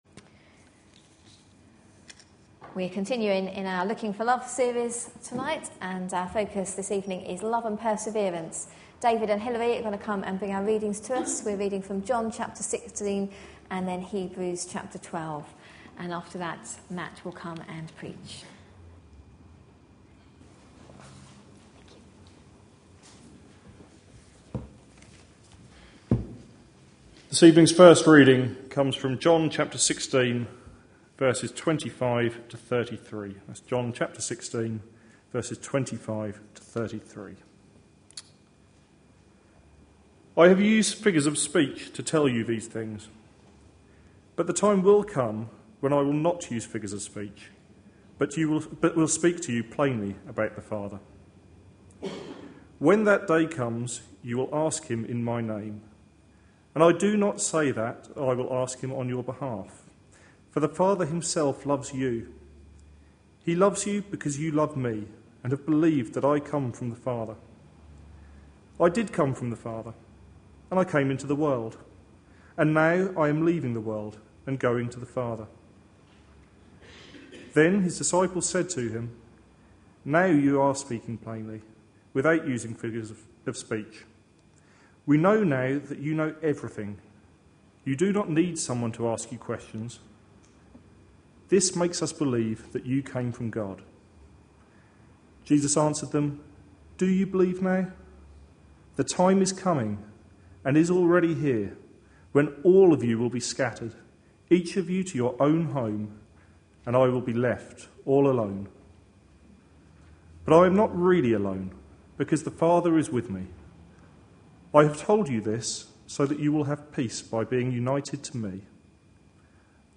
A sermon preached on 11th March, 2012, as part of our Looking For Love (6pm Series) series.